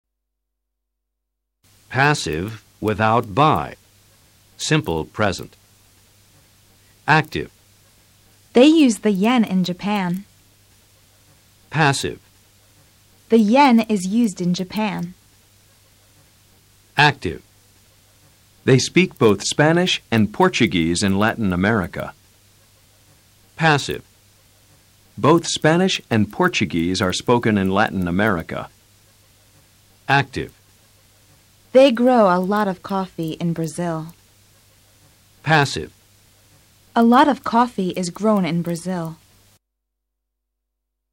Escucha atentamente a los profesores leyendo oraciones en VOZ ACTIVA y PASIVA.